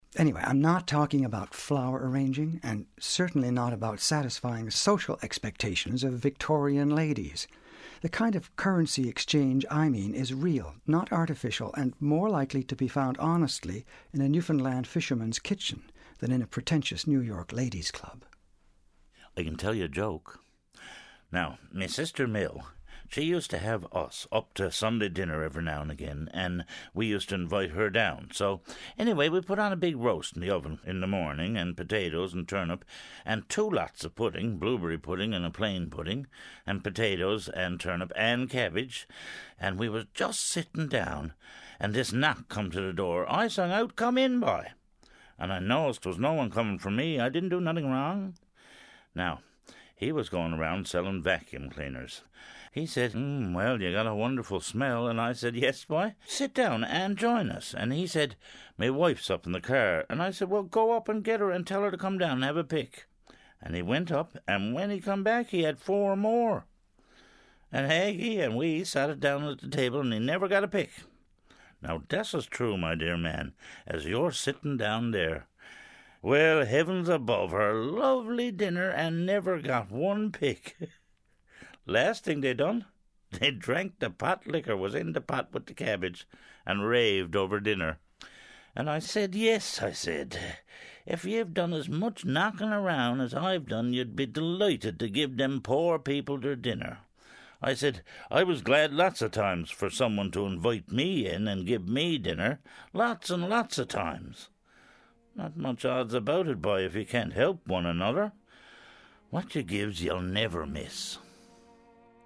Tilting is a remote village on Fogo Island, eight miles off the Newfoundland shore.
reading